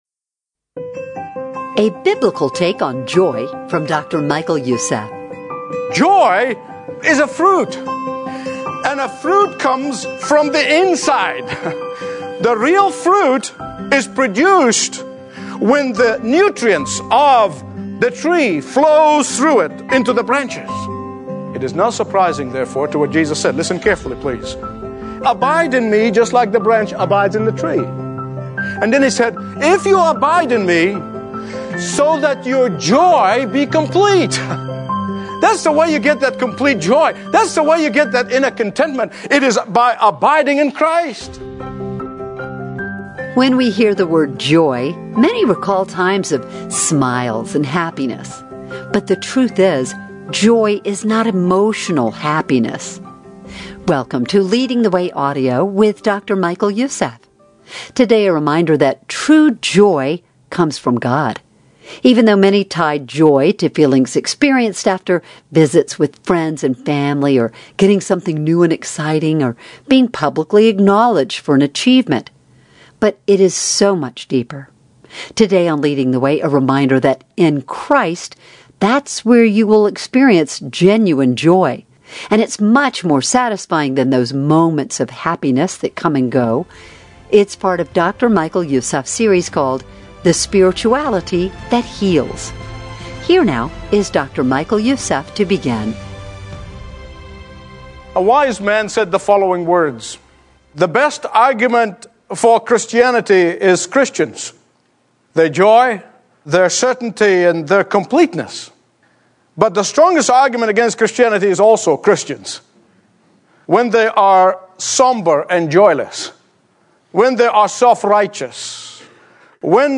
Stream Expository Bible Teaching & Understand the Bible Like Never Before.